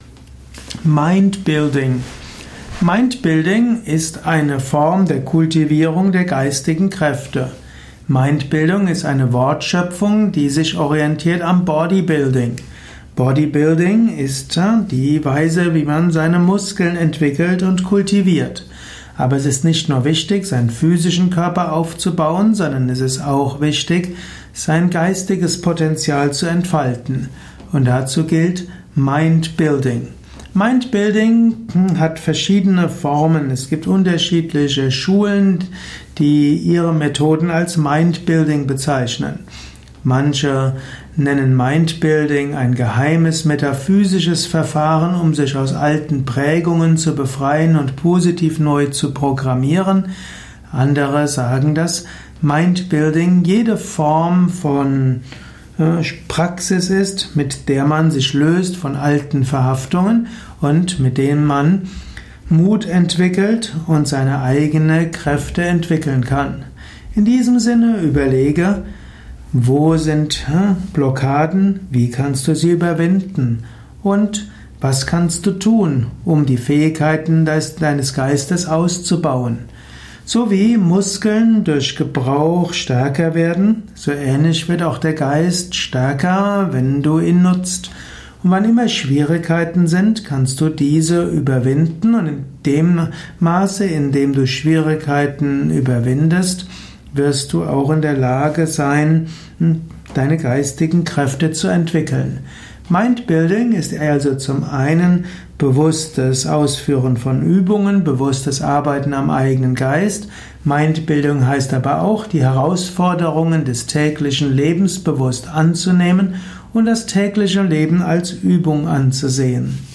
Ein Vortrag über Mindbuilding, Teil des Yoga Vidya Lexikons der Tugenden, Persönlichkeit und Ethik.